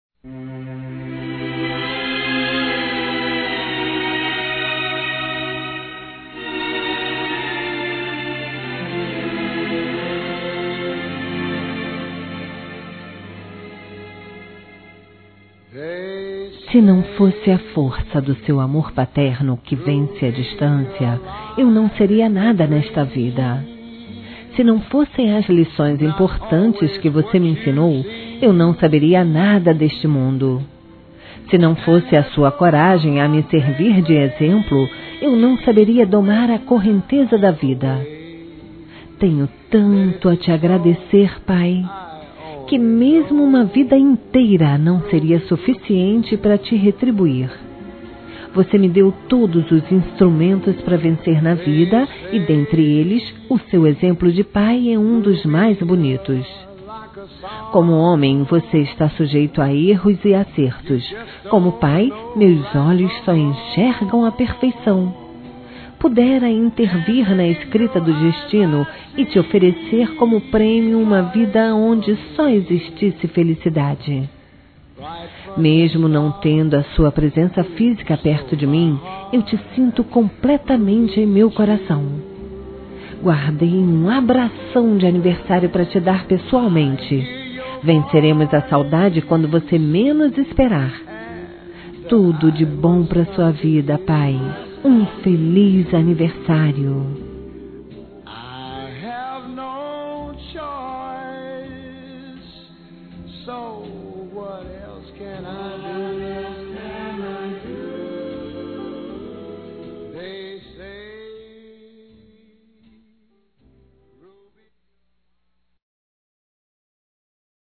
Telemensagem de Aniversário de Pai – Voz Feminina – Cód: 1485 – Distante